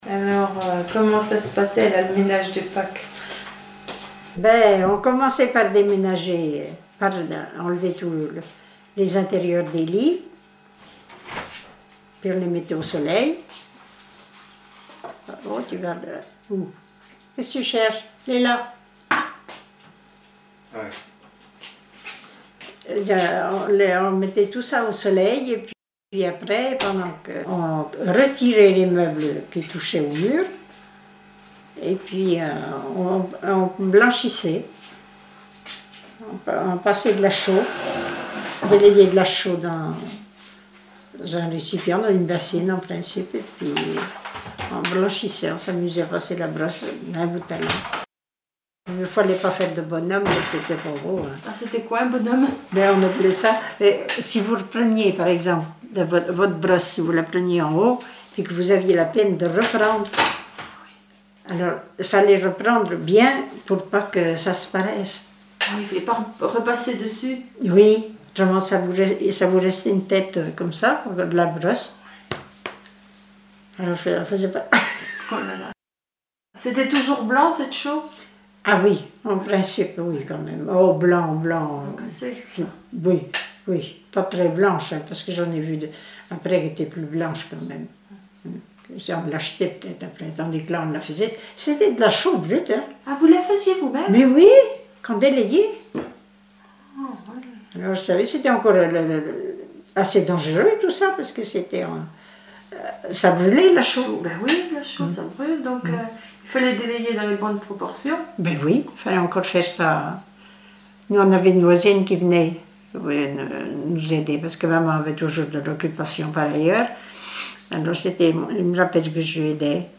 Témoignages sur les tâches ménagères
Catégorie Témoignage